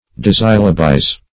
Search Result for " dissyllabize" : The Collaborative International Dictionary of English v.0.48: Dissyllabize \Dis*syl"la*bize\, v. t. To form into two syllables; to dissyllabify.
dissyllabize.mp3